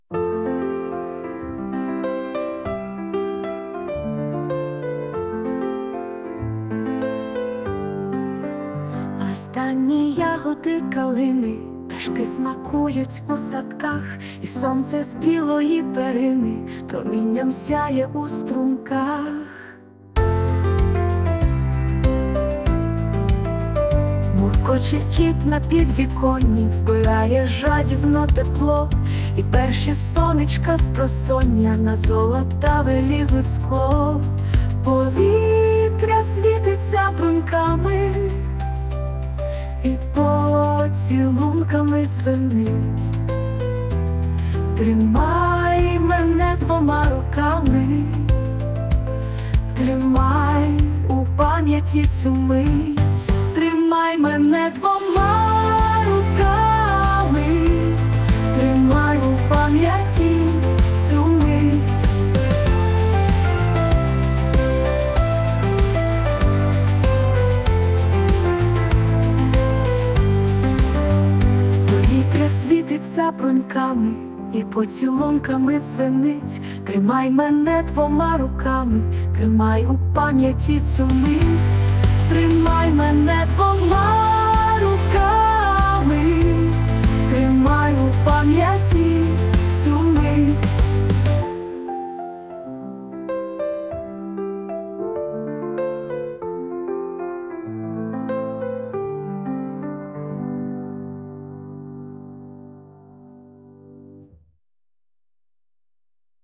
Музика і виконання - ШІ
ТИП: Пісня
СТИЛЬОВІ ЖАНРИ: Ліричний